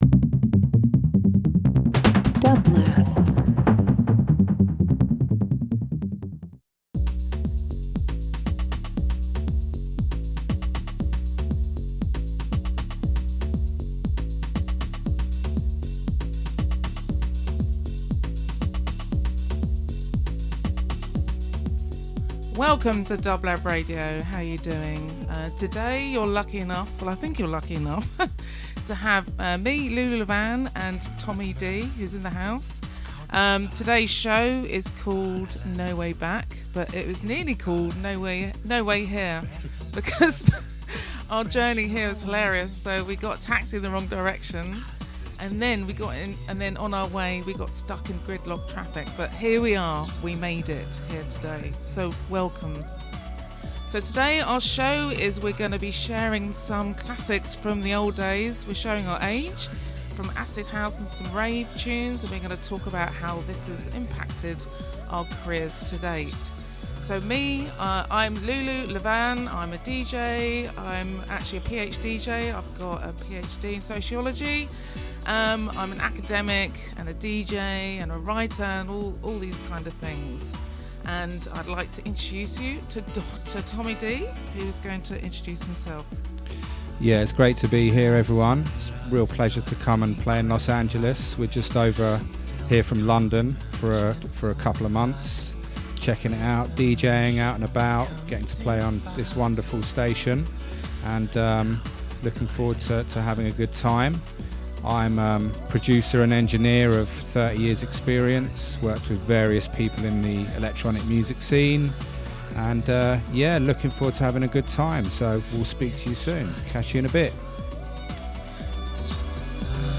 Acid Electronic House